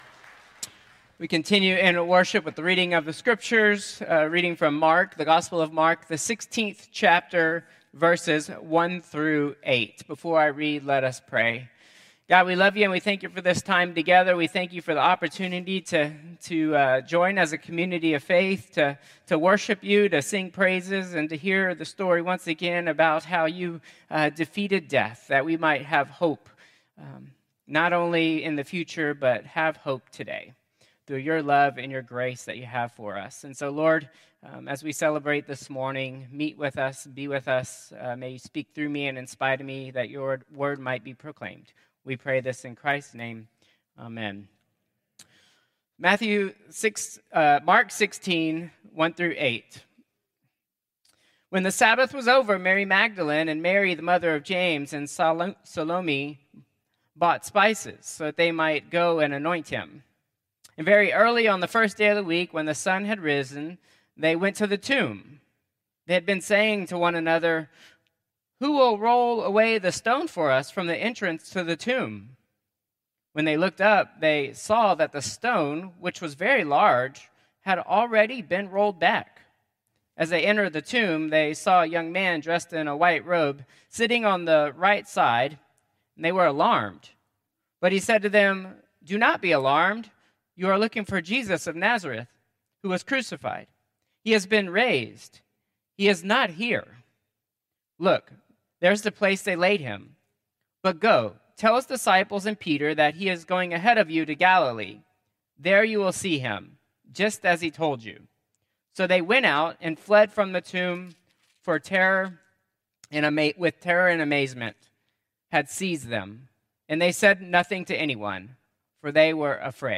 Contemporary Easter Worship 4/20/2025